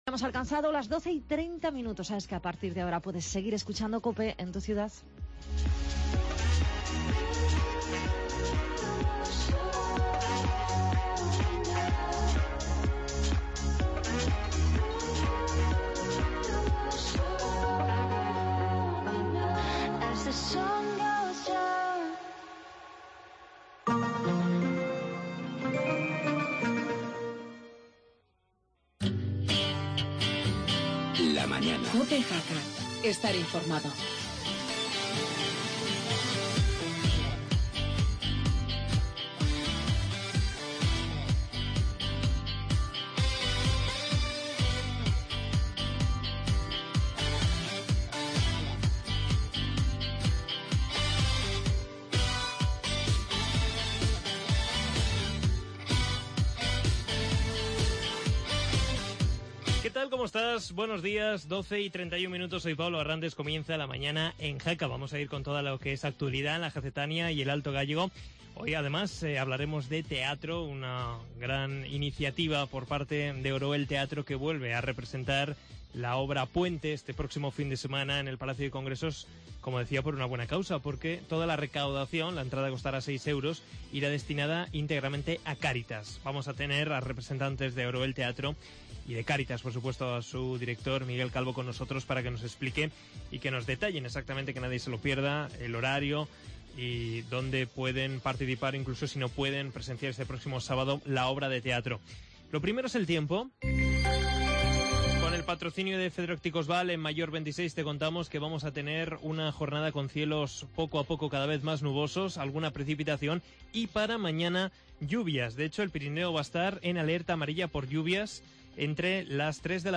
entrevista
tertulia agraria